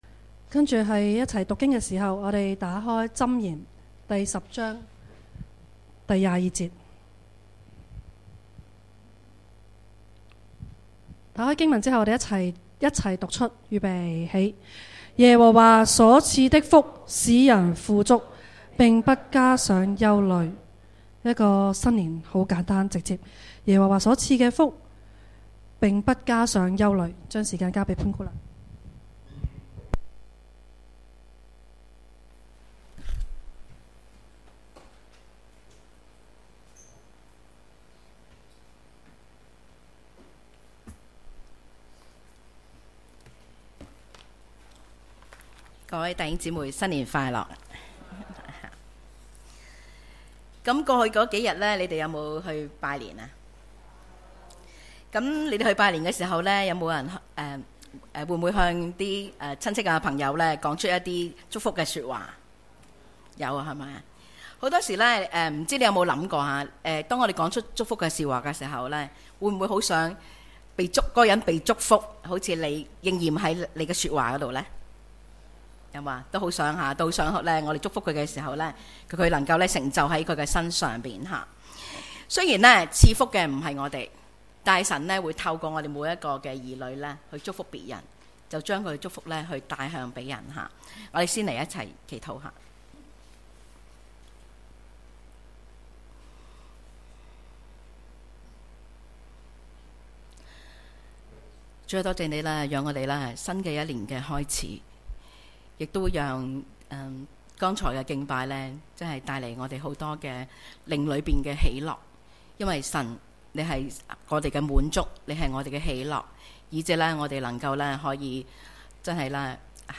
主日崇拜講道 – 神所賜的福使人富足並不加上憂慮